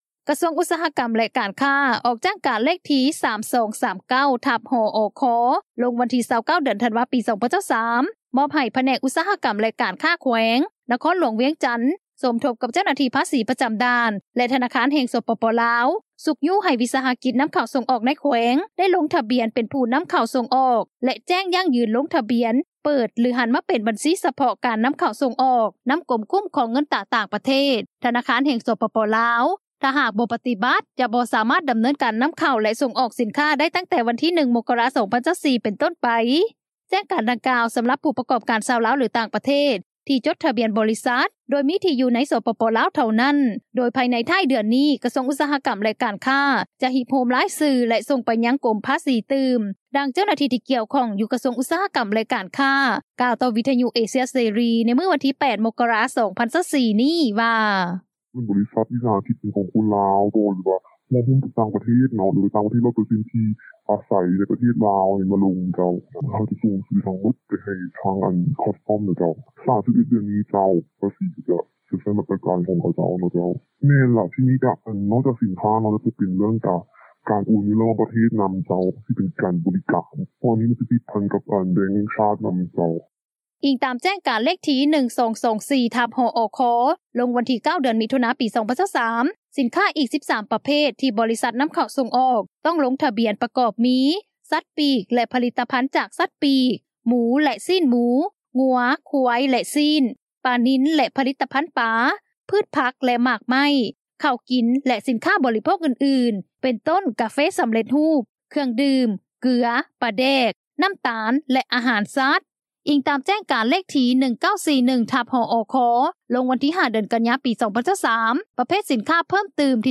ດັ່ງເຈົ້າໜ້າທີ່ ທີ່ກ່ຽວຂ້ອງ ຢູ່ແຂວງບໍ່ແກ້ວ ທ່ານນຶ່ງ ກ່່າວວ່າ:
ດັ່ງເຈົ້າໜ້າທີ່ ທີ່ກ່ຽວຂ້ອງ ກະຊວງການເງິນທ່ານນຶ່ງ ກ່່າວວ່າ: